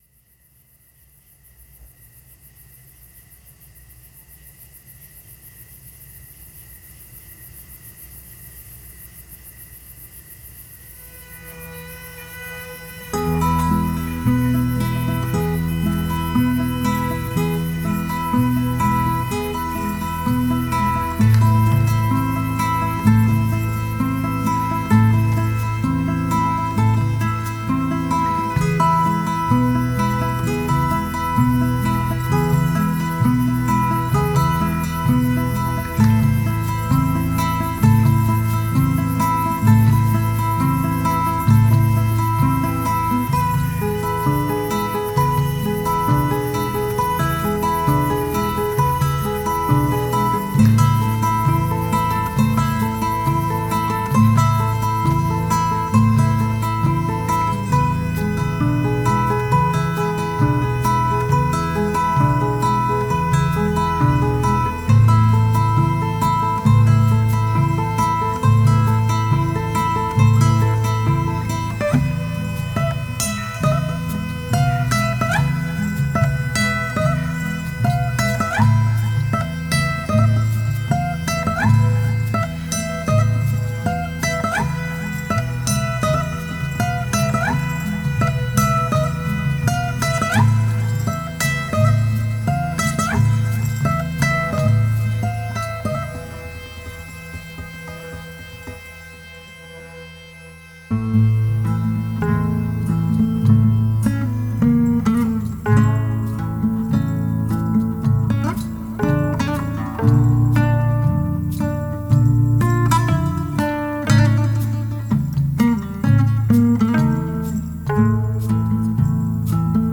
Дуэт двух мульти-инструменталистов
Жанр: Фолк, Шансон, Романс, Акустика